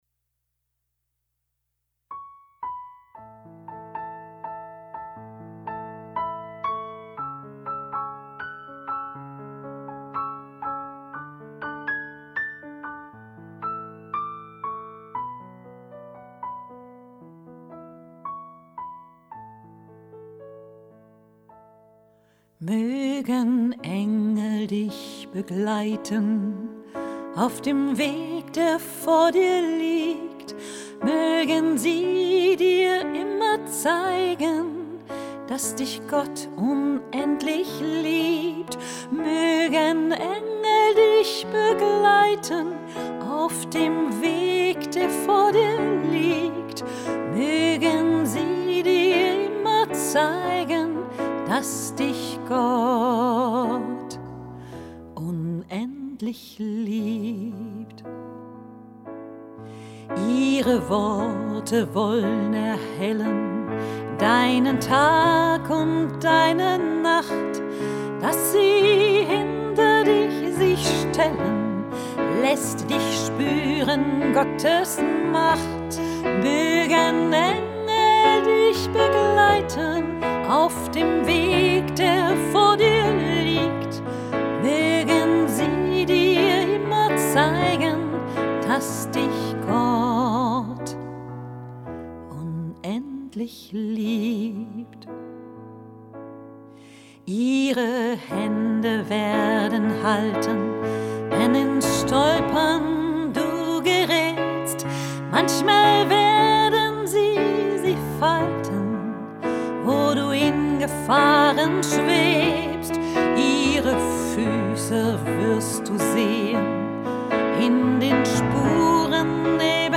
Hochzeitssängerin Hannover/Niedersachsen